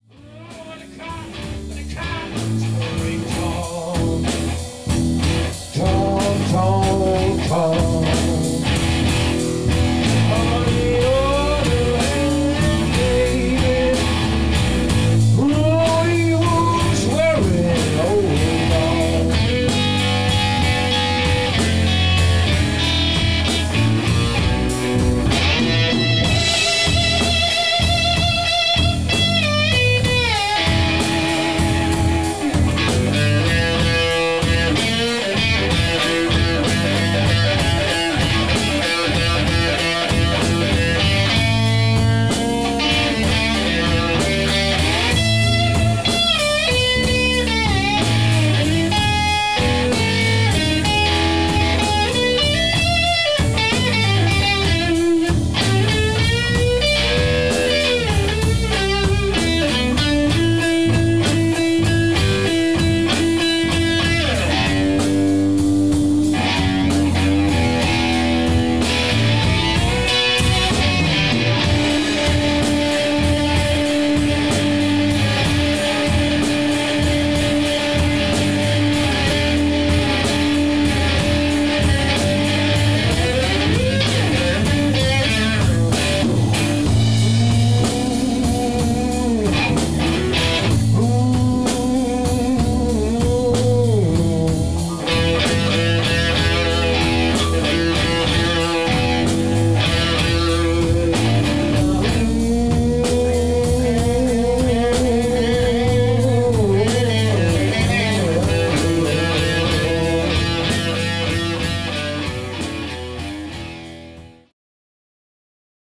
rock and hard rock
vocals, guitar
bass, backing vocals
drums, percussion
Mitschnitte aus dem Proberaum